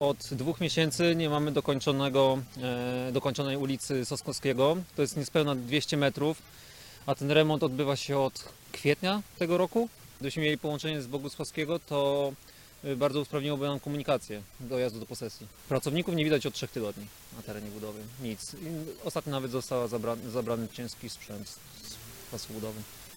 Mówi jeden z mieszkańców ul. Sosnkowskiego: